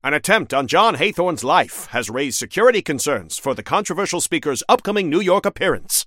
File:Newscaster headline 54.mp3 - The Deadlock Wiki
Newscaster_headline_54.mp3